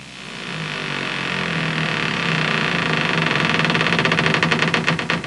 Creaking Door Sound Effect
Download a high-quality creaking door sound effect.
creaking-door-1.mp3